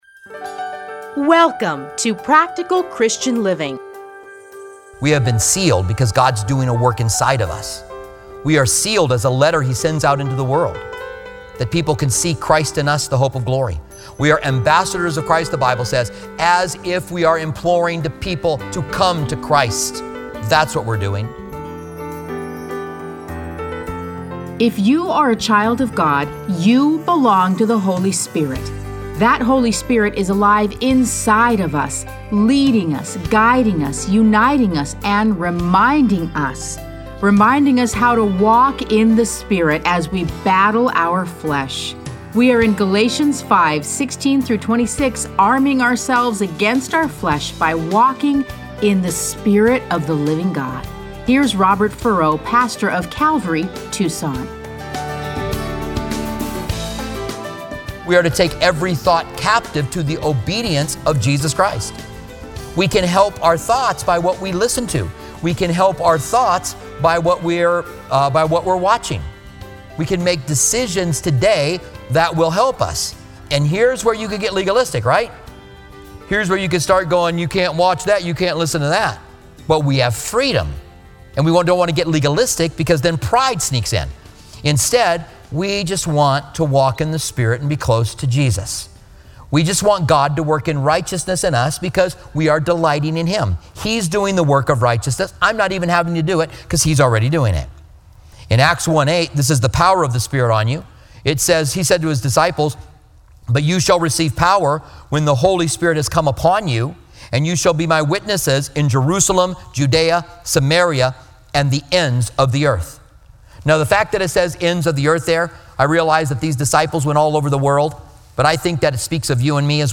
Listen to a teaching from Galatians 5:16-26.